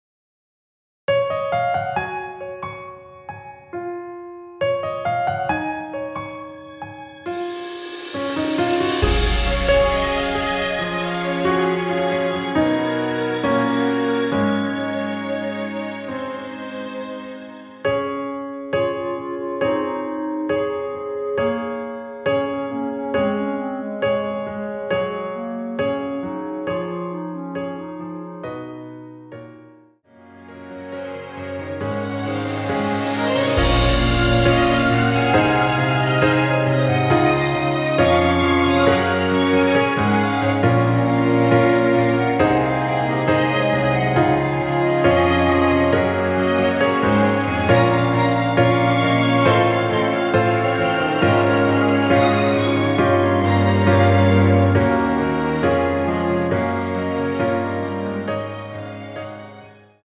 남성분이 부르실 수 있는 키의 MR입니다.
원키에서(-8)내린 멜로디 포함된 MR입니다.(미리듣기 확인)
Db
앞부분30초, 뒷부분30초씩 편집해서 올려 드리고 있습니다.
중간에 음이 끈어지고 다시 나오는 이유는